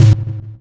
se_damage01.wav